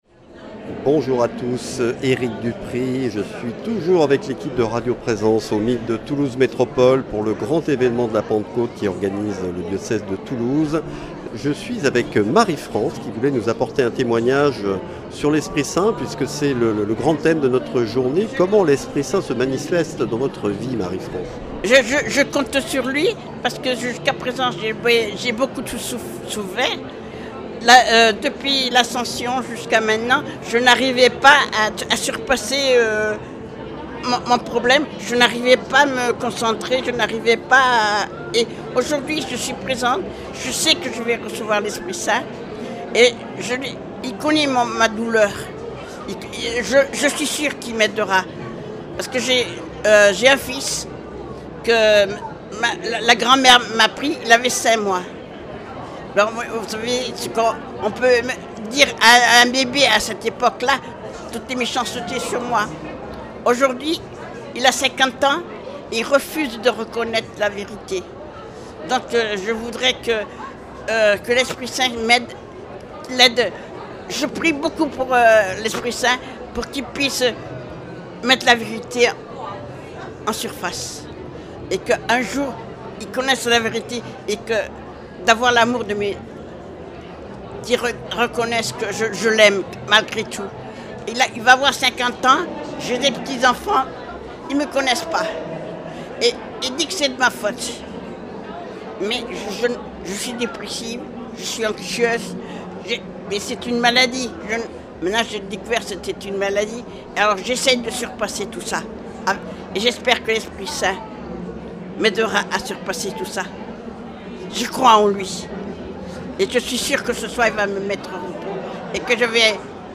L’Esprit Saint, source de vie – Entretien
Dans le cadre de Pentecôte 2025 au MEETT de Toulouse